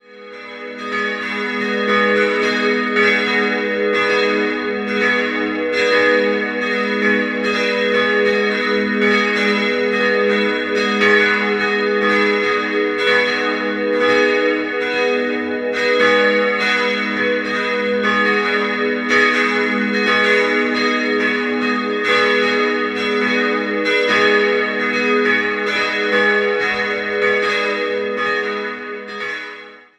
Der Ort entstand um das Jahr 1600 an einem Eisenhammer, die Emmauskirche wurde im Jahr 1889 errichtet. 3-stimmiges Geläute: gis'-h'-dis'' Die Glocken wurden 2013 von der Firma Grassmayr in Innsbruck gegossen und ersetzen das Eisenhartgussgeläut aus dem Jahr 1921.